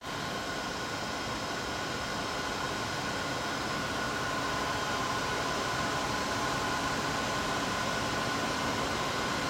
запись серверной с вентиляторами и оборудованием
zapis_servernoj_s_ventilyatorami_i_oborudovaniem_efq.mp3